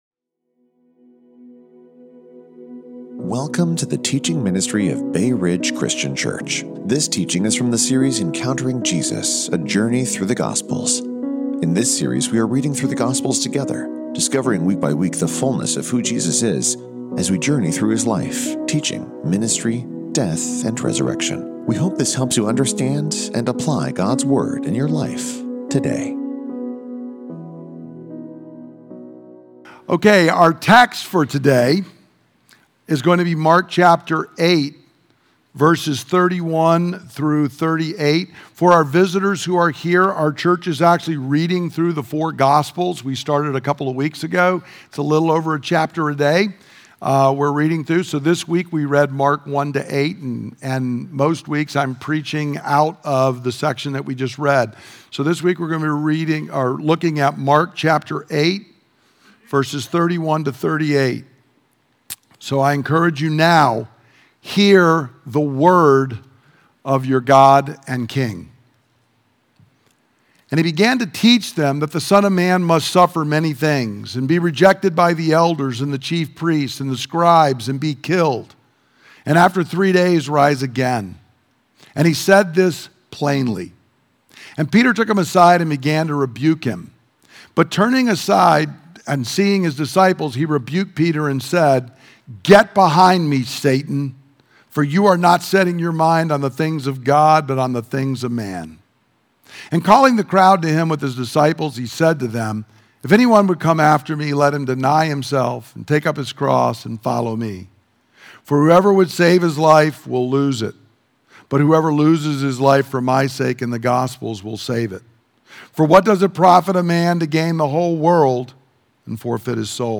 Listen to the teaching – Join us on Facebook or YouTube Live on Sunday @ 10:00 am